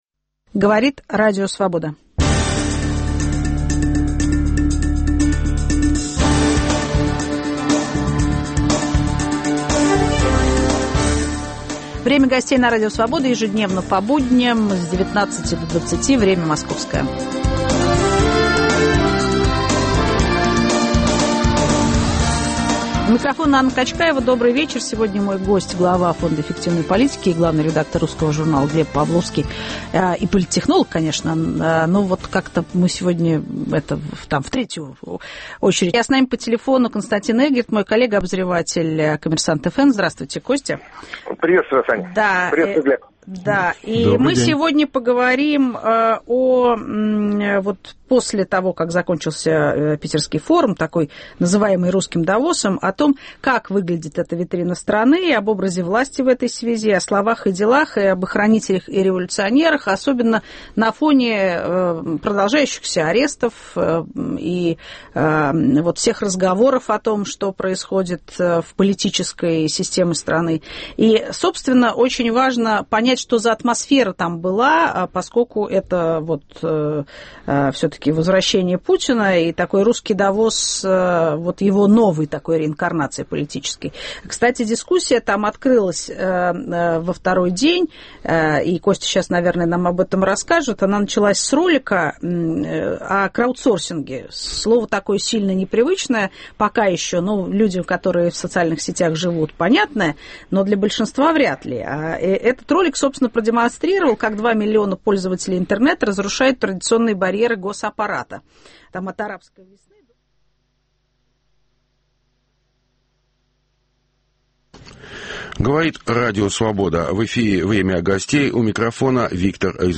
Обсуждаем с правозащитником Юлием Рыбаковым.